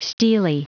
Prononciation du mot stelae en anglais (fichier audio)
Prononciation du mot : stelae